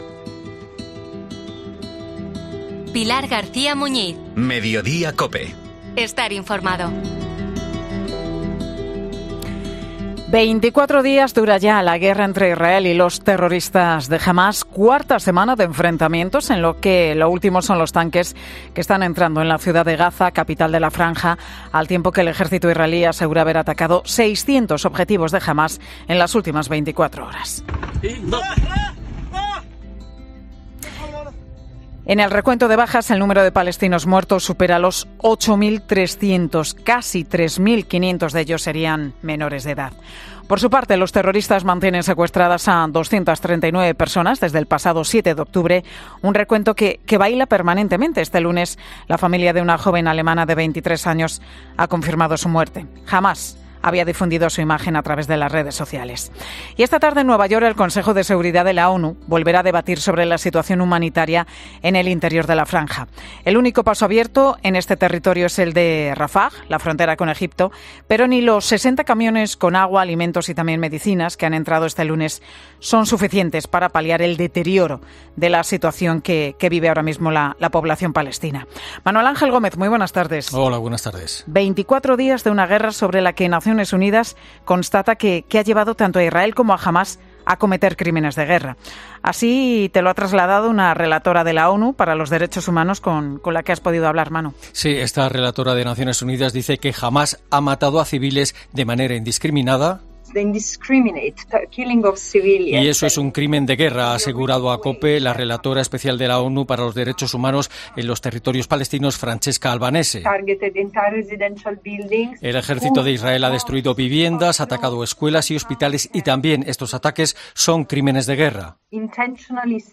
"Hamás e Israel están cometiendo crímenes de guerra", asegura a COPE Franceca Albanese, relatora ONU